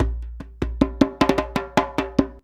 100DJEMB13.wav